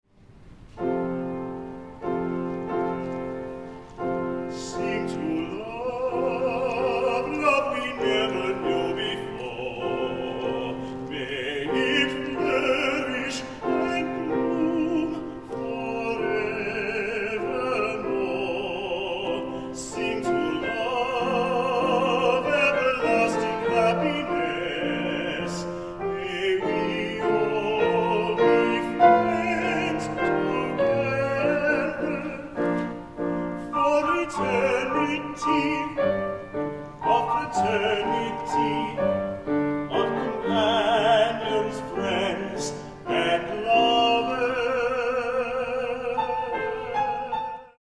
soprano
tenor